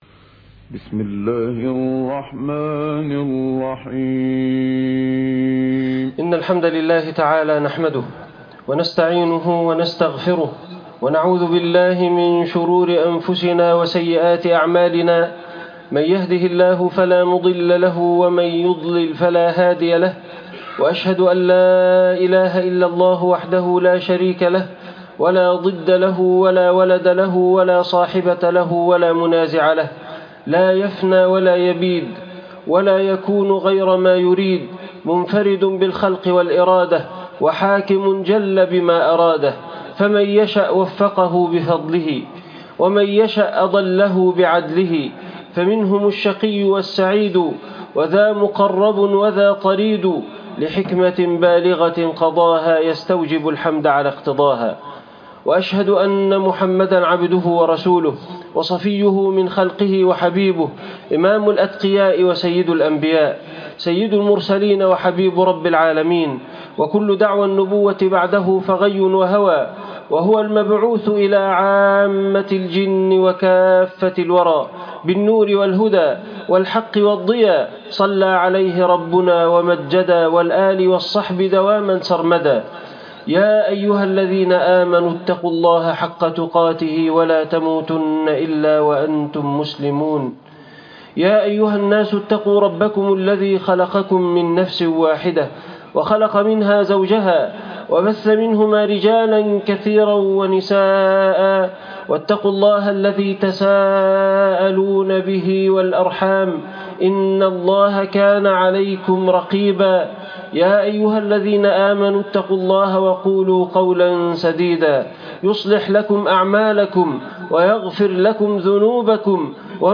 عنوان المادة إنه القرآن - الإيمان بالغيب خطبة جمعة تاريخ التحميل الجمعة 6 سبتمبر 2024 مـ حجم المادة 14.82 ميجا بايت عدد الزيارات 182 زيارة عدد مرات الحفظ 85 مرة إستماع المادة حفظ المادة اضف تعليقك أرسل لصديق